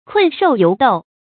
注音：ㄎㄨㄣˋ ㄕㄡˋ ㄧㄡˊ ㄉㄡˋ
讀音讀法：